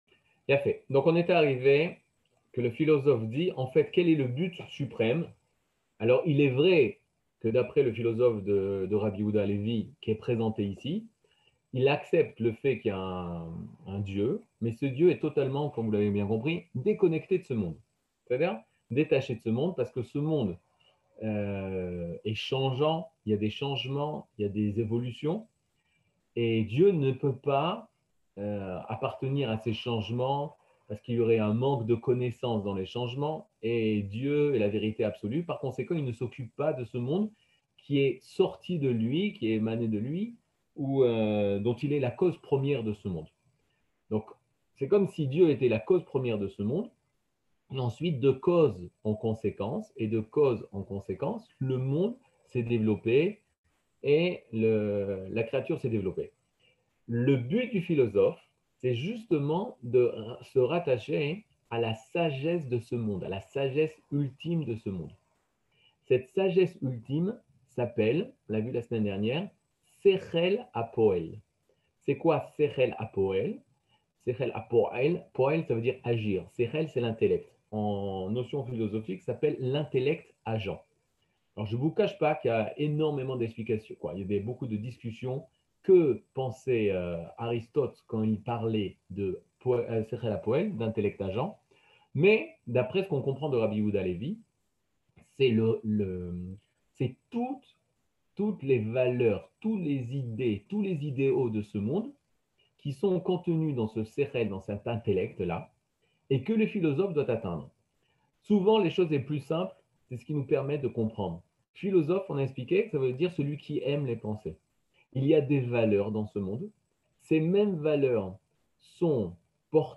Catégorie Le livre du Kuzari partie 6 00:59:03 Le livre du Kuzari partie 6 cours du 16 mai 2022 59MIN Télécharger AUDIO MP3 (54.05 Mo) Télécharger VIDEO MP4 (98.3 Mo) TAGS : Mini-cours Voir aussi ?